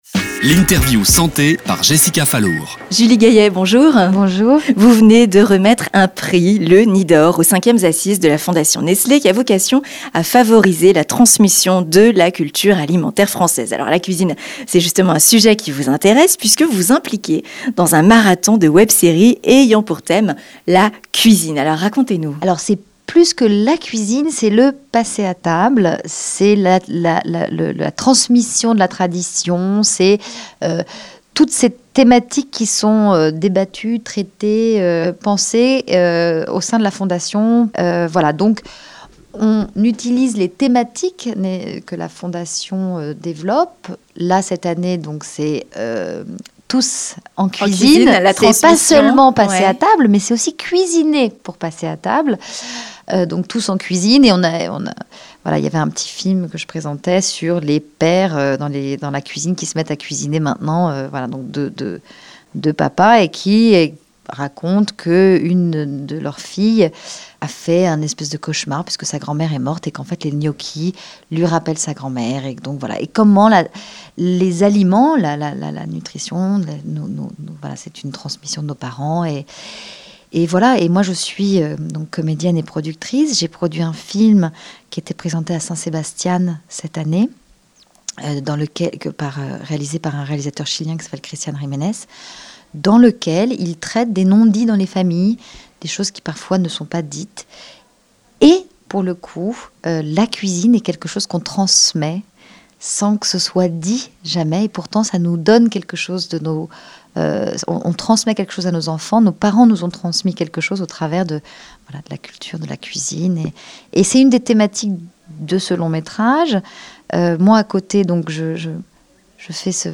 Interview de Julie Gayet autour de la cuisine et de la santé
Itw_Julie-Gayet.mp3